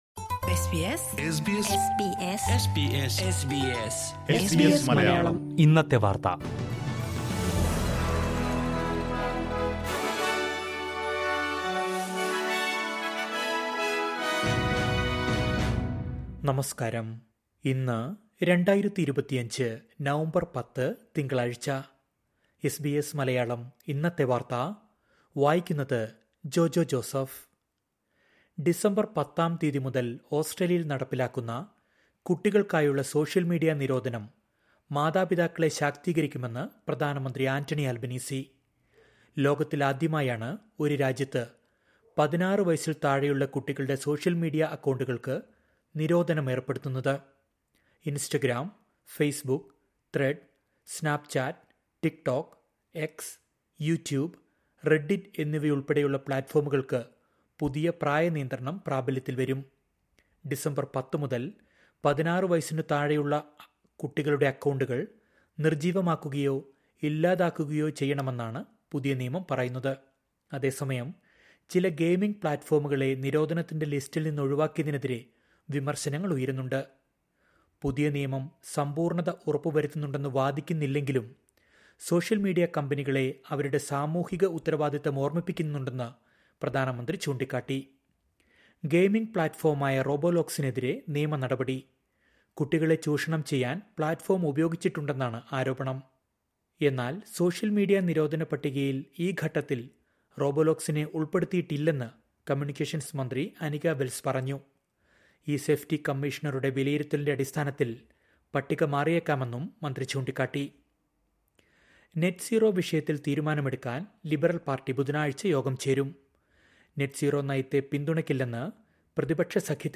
2025 നവംബർ 10ലെ ഓസ്ട്രേലിയയിലെ ഏറ്റവും പ്രധാന വാർത്തകൾ കേൾക്കാം...